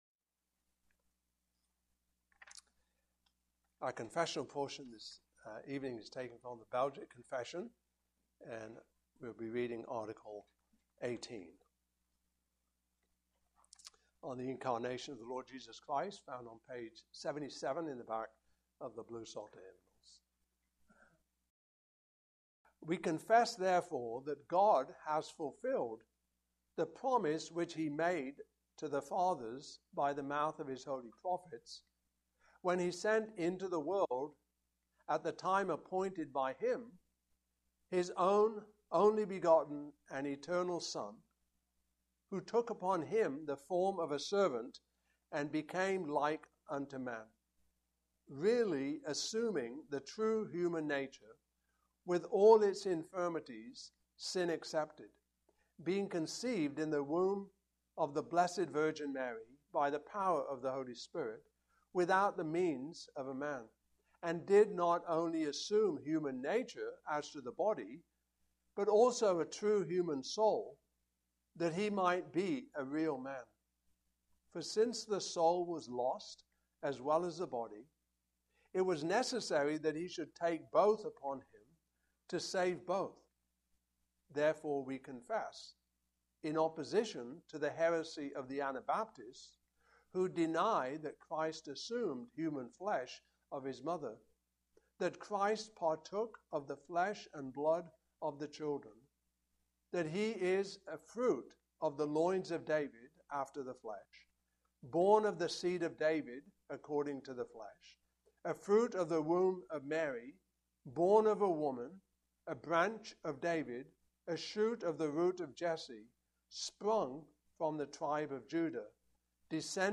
Passage: Philippians 2:5-11 Service Type: Evening Service